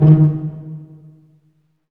STR PIZZ.0AL.wav